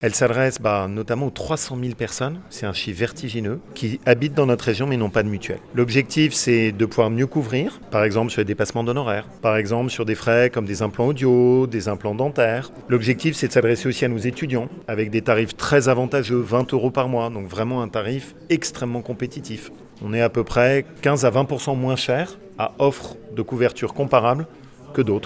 Laurent Wauquiez nous présente le dispositif :